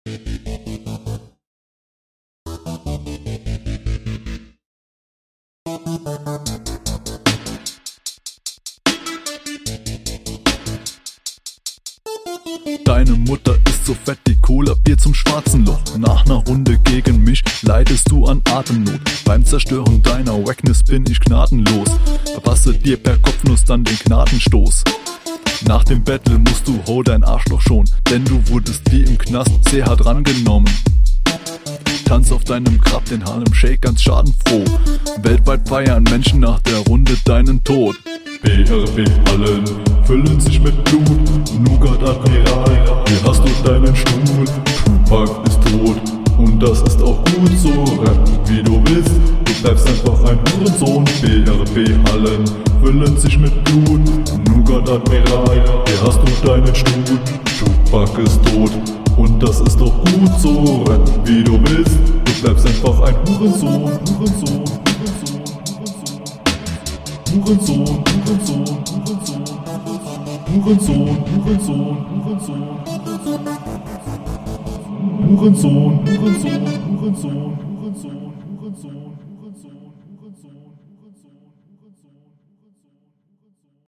Flow ist in Ordnung, nichts besonders gutes aber eben auch nicht schlecht.